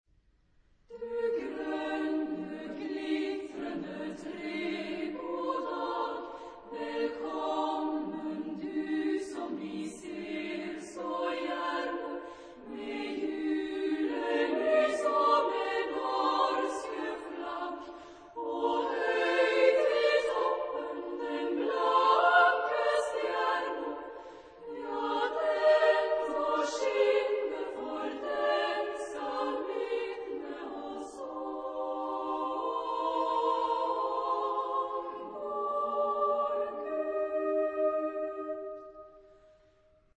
Genre-Style-Form: Popular ; Lied
Mood of the piece: allegretto
Type of Choir: SSA  (3 women voices )
Tonality: E flat major